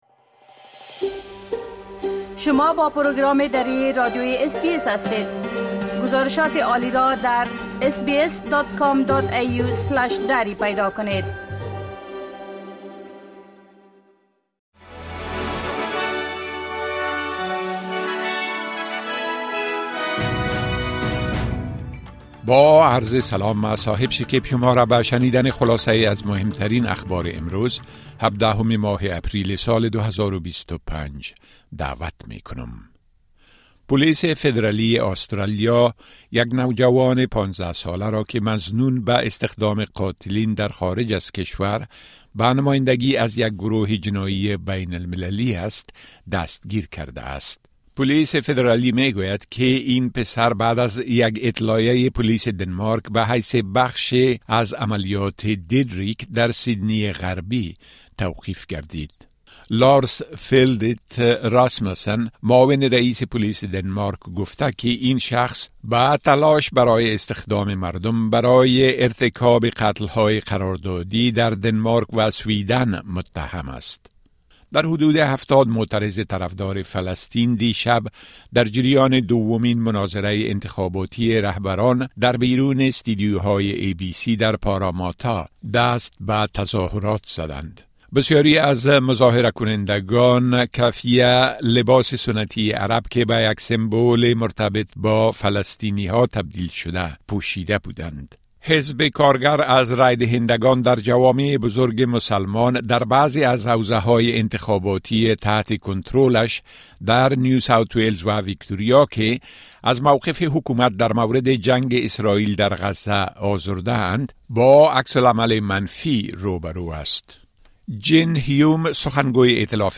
خلاصۀ مهمترين اخبار روز از بخش درى راديوى اس بى اس